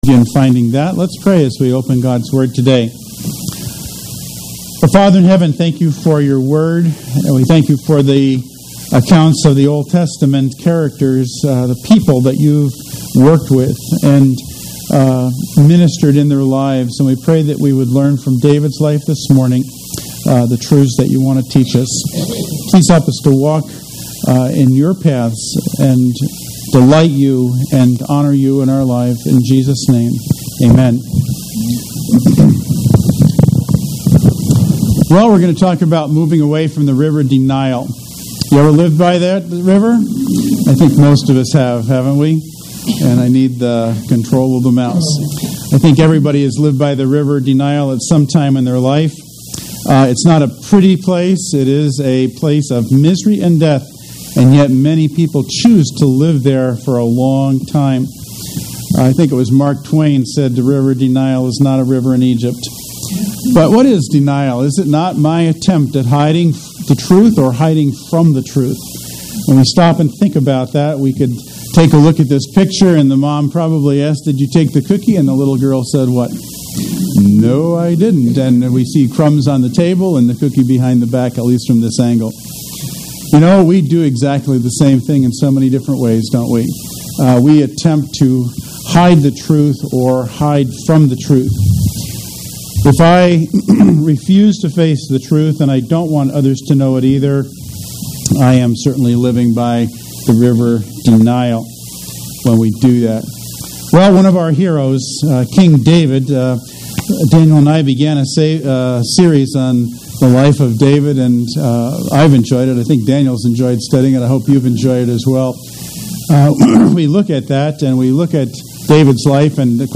Take a few moments to listen to this sermon and be encouraged and challenged in your own spiritual walk and your response to sin.